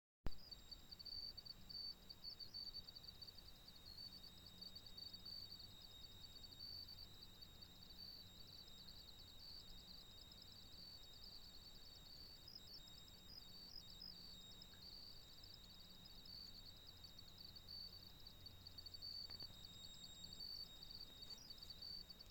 J’ai également ce sifflement, qui est très pénible, car au bout d’un moment, on finit par se focaliser dessus.
J'ai amplifié votre enregistrement pour mieux entendre les grillons... on se croirait en Provence... et au bord d'une autoroute. :-)
sifflement-carte-elecrtonique.mp3